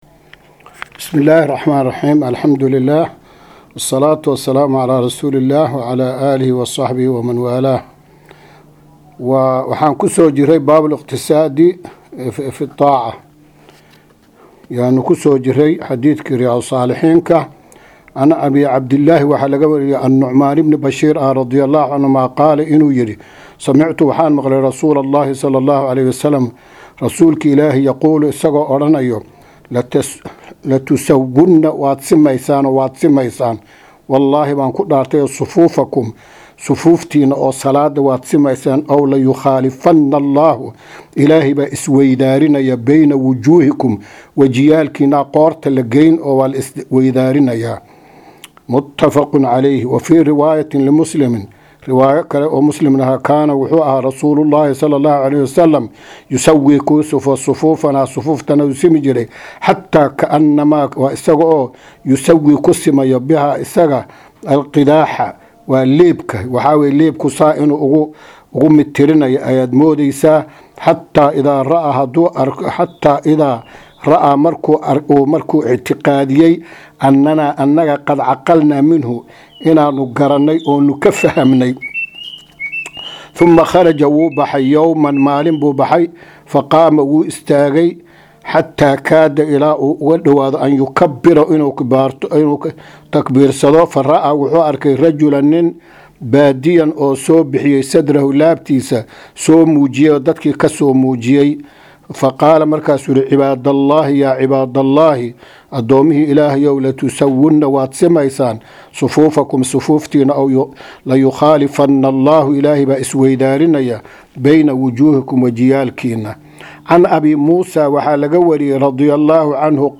Maqal- Riyaadu Saalixiin – Casharka 16aad